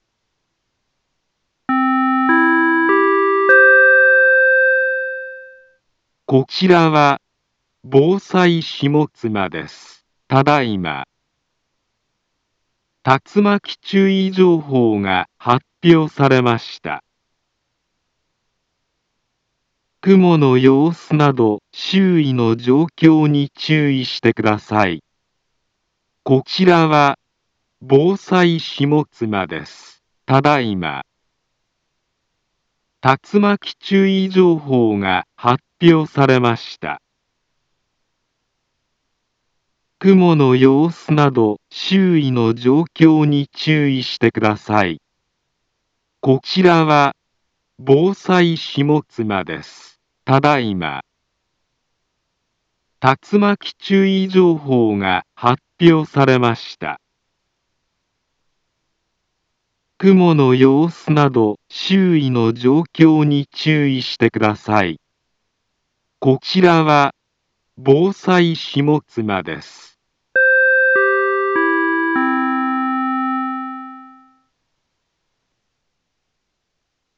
Back Home Ｊアラート情報 音声放送 再生 災害情報 カテゴリ：J-ALERT 登録日時：2022-07-03 13:55:14 インフォメーション：茨城県北部、南部は、竜巻などの激しい突風が発生しやすい気象状況になっています。